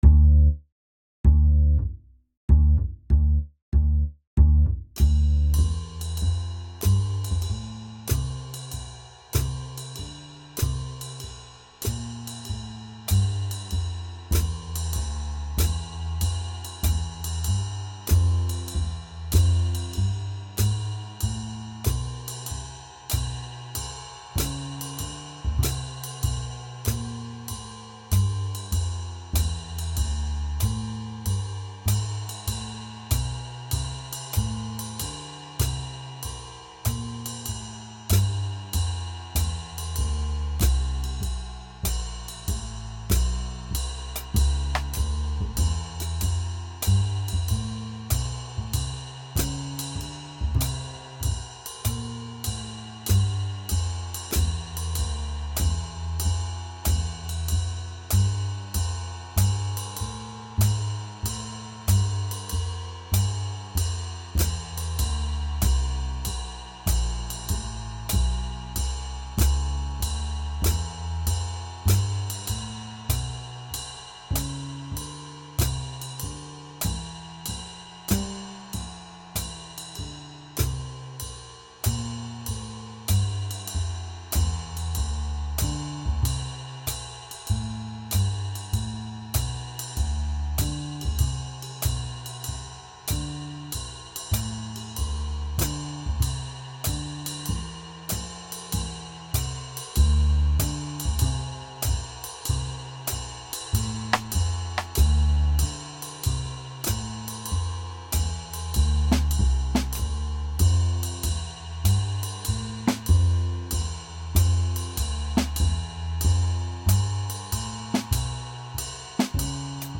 Here’s a bass and drum track to play Lesson 1 along with